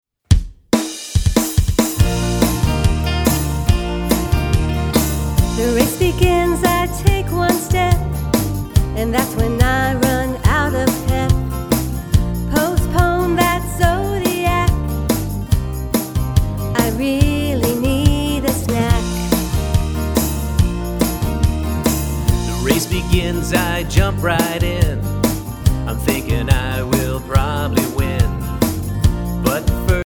*  Catchy melodies, dumb jokes, interesting stories
song clip